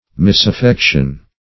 Search Result for " misaffection" : The Collaborative International Dictionary of English v.0.48: misaffection \mis`af*fec"tion\ (m[i^]s`[a^]f*f[e^]k"sh[u^]n), n. An evil or wrong affection; the state of being ill affected.